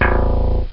B Gtr Sound Effect
b-gtr.mp3